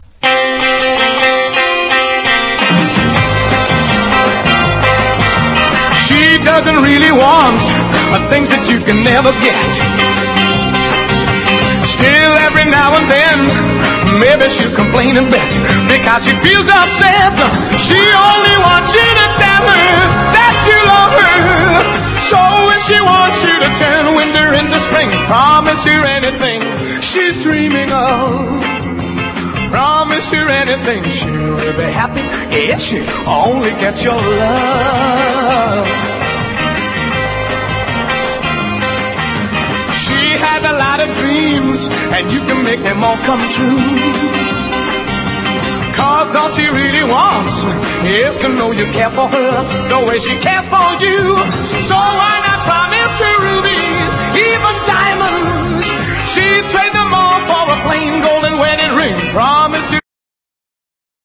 JAZZ / EASY LISTENING
コーラス入りのポップな名作！
その後は太いベースを軸に展開されるスウィングやティファナ・サウンド、キュートなスキャットに打ちのめされるはず。